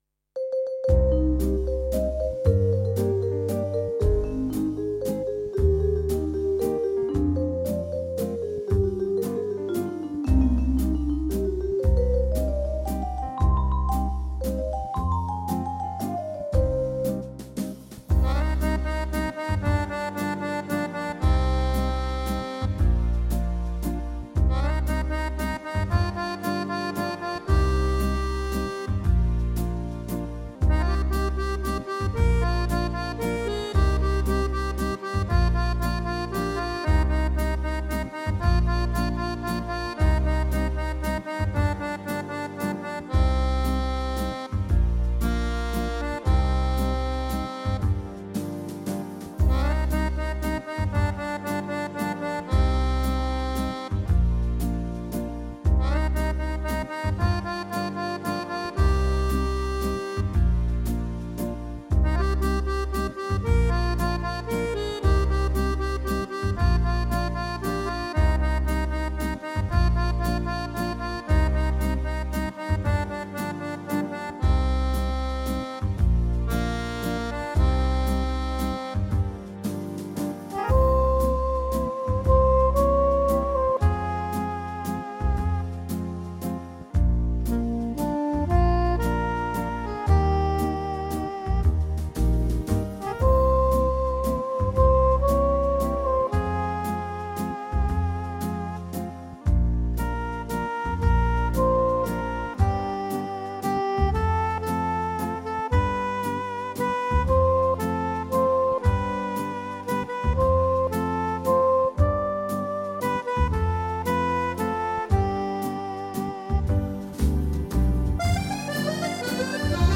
Joué sur le tyros 5 avec le style : VocalWaltz
- vibraphone
- JazzAccordion
- BalladSoprano (sax)
- FrenchMusette + Steirish (accordéons)
- PopEnsemble + ScatVocalists (Choir)